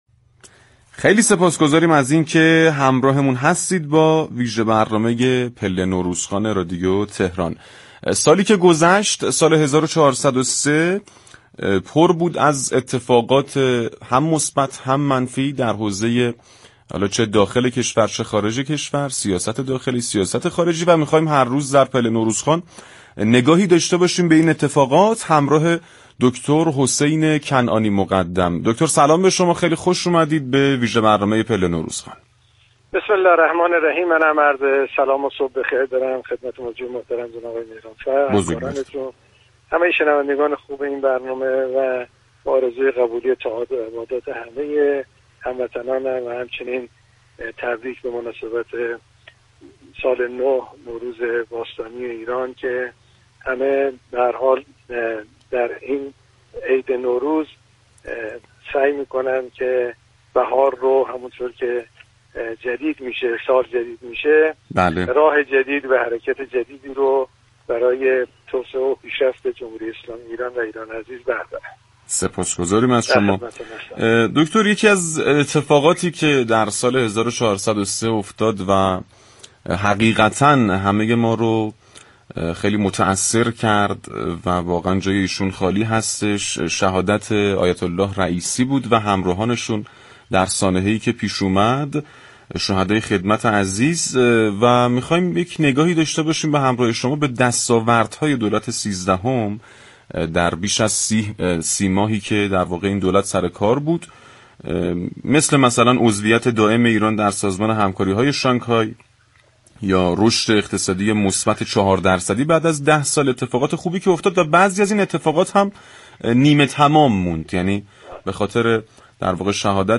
گفت و گو با رادیو تهران